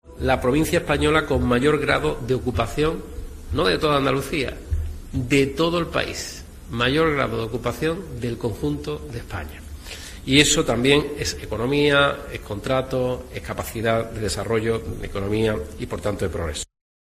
En declaraciones a los periodistas, Moreno ha subrayado que por primera vez desde hace 22 meses se ha superado el millón de estancias hoteleras en la provincia, con un 66% más que en julio del año pasado.